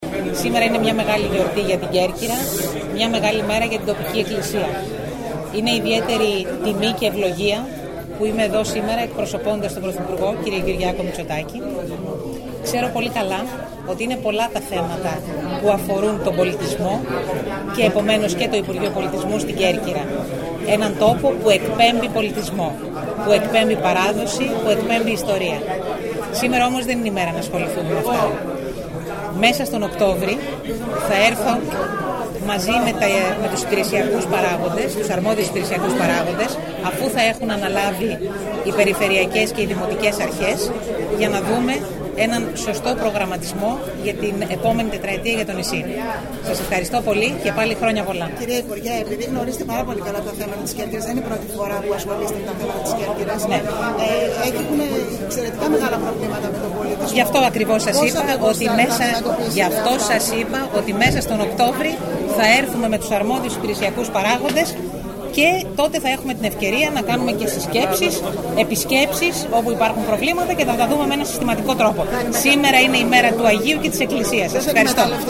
Η υπουργός πολιτισμού Λίνα Μενδώνη στις δηλώσεις της αμέσως μετά το πέρας της λιτανείας επεσήμανε ότι τα θέματα που αφορούν την Κέρκυρα είναι πολλά  και προανήγγειλε ότι μέσα στον Οκτώβριο θα επισκεφθεί εκ νέου το νησί μαζί με τους αρμόδιους υπηρεσιακούς παράγοντες προκειμένου, σε συνεργασία με τους νέους εκπροσώπους της αυτοδιοίκησης Α και Β βαθμού, να γίνει ένας σωστός προγραμματισμός 4ετίας για το νησί.